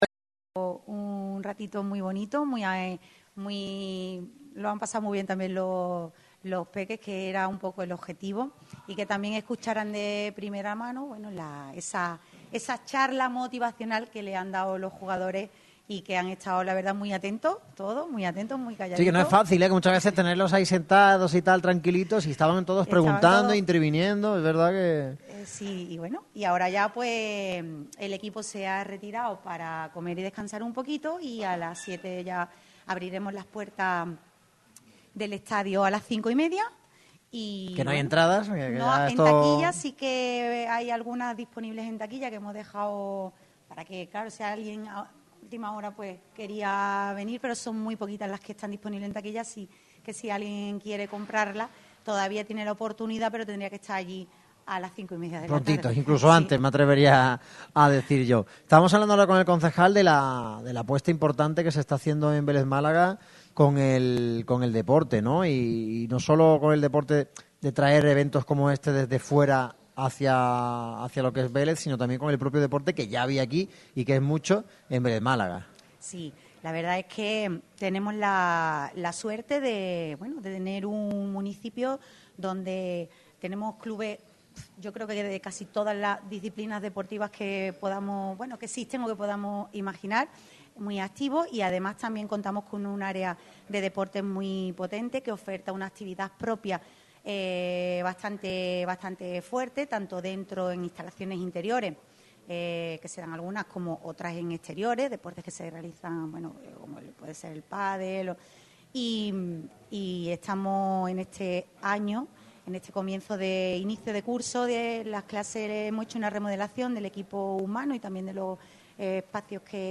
La Concejala Delegada de Medio Ambiente, Deportes y Movilidad de Vélez, Rocío Ruíz Narváez, ha pasado este viernes por el programa especial de Radio MARCA Málaga en el Pabellón Fernando Hierro de la localidad malagueña.